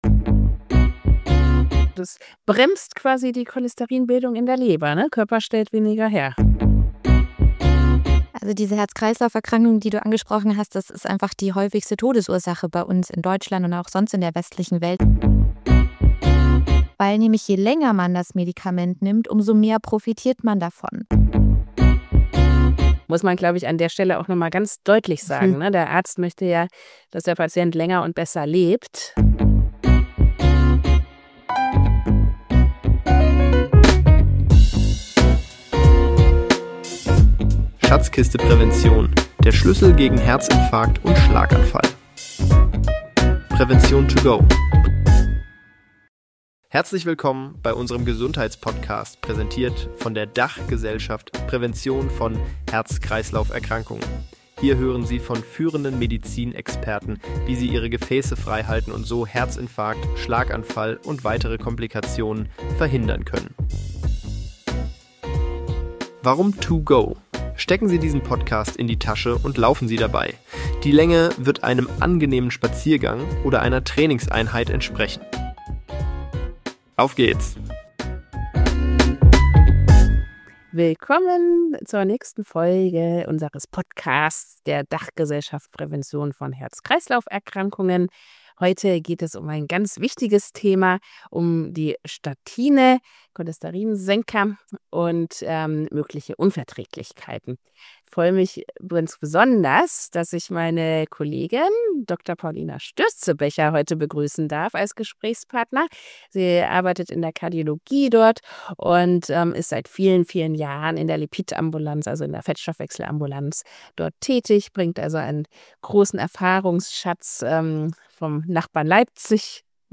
Sie sprechen über die Rolle des LDL-Cholesterins im Körper, über gute Langzeitergebnisse bei der Gabe von Statinen und über die sorgsame Abwägung bei der Entscheidung für diese Therapie. Im Fall von Nebenwirkungen gibt es gute Ansätze, um nicht auf das Medikament verzichten zu müssen. Statine sind eines der besten Beispiele für vorbeugende Medizin, sagen die beiden Expertinnen.